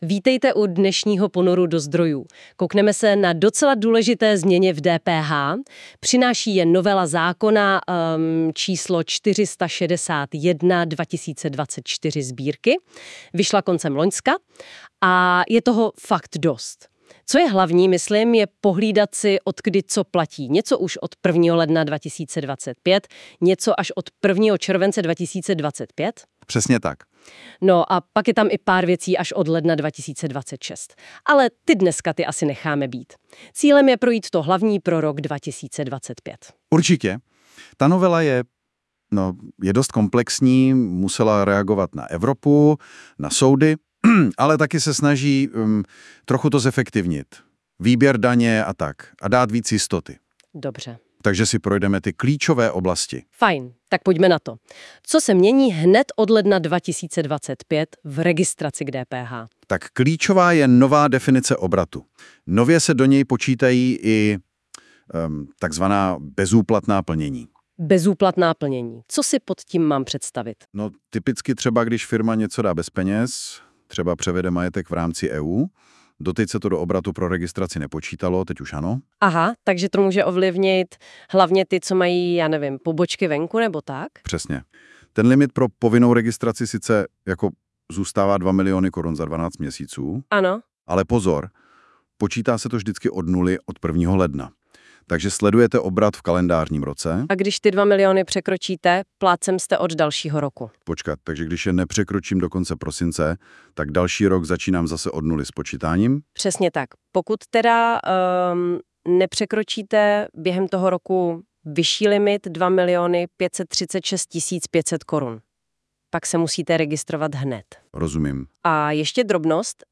Právní upozornění: podcasty s informačními souhrny nejdůležitějších změn byly vytvořeny nástrojem NotebookLM společnosti Google a na těchto stránkách běží ve zkušebním provozu.